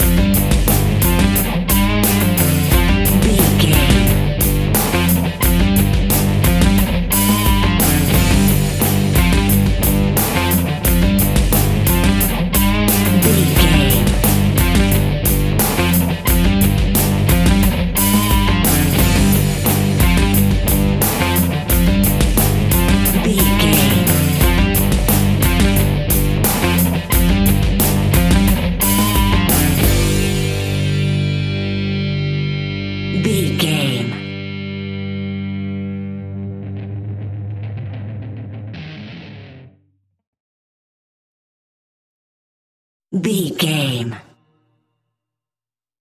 Powerful Rock Music Track 30 Sec.
Epic / Action
Aeolian/Minor
heavy rock
Rock Bass
heavy drums
distorted guitars
hammond organ